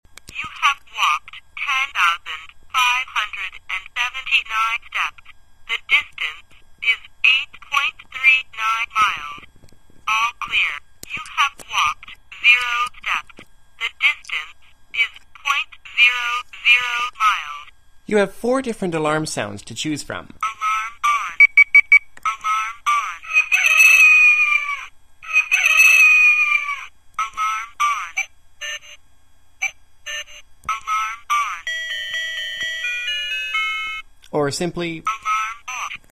• Small and light, with clear female voice.
A clear, female voice announces how many steps you've taken, and how many miles you've traveled.
talking_pedometer_clock.mp3